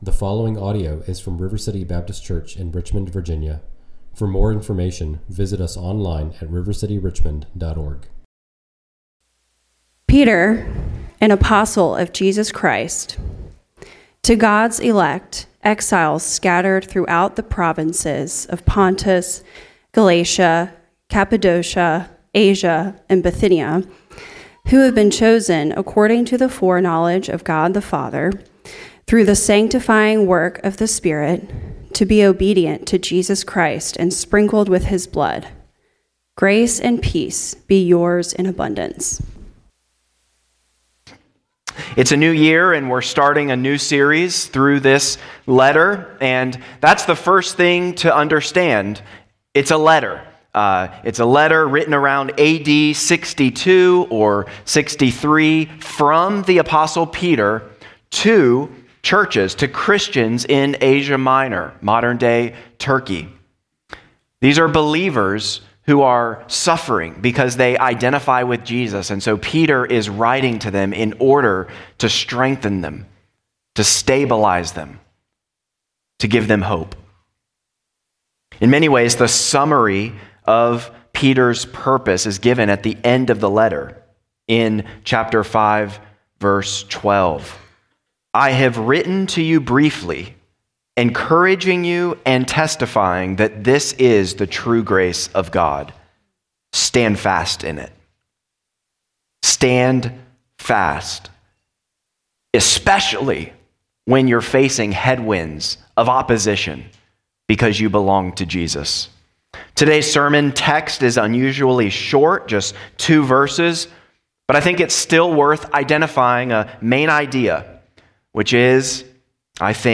at River City Baptist Church, a new congregation in Richmond, Virginia.